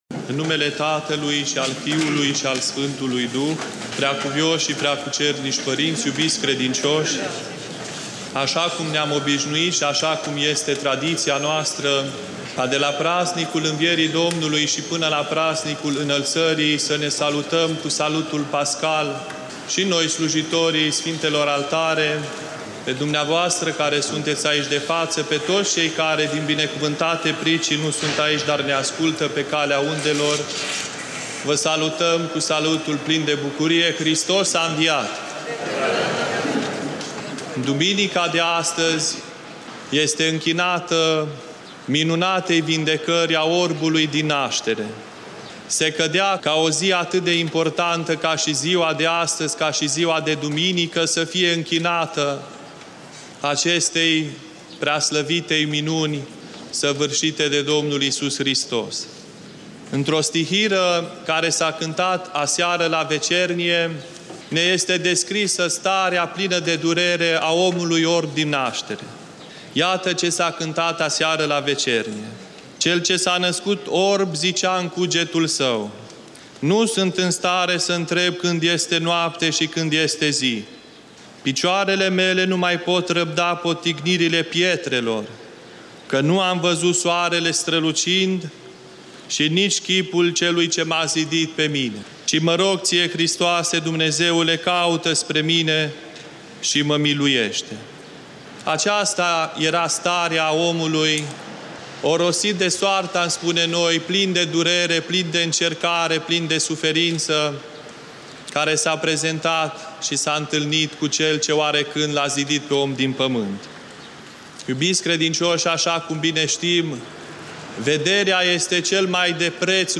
Predică la Duminica a VI-a după Paști (a Orbului din naștere)
la Catedrala Mitropolitană din
Cuvinte de învățătură Predică la Duminica a VI-a după Paști